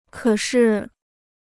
可是 (kě shì): but; however; (used for emphasis) indeed.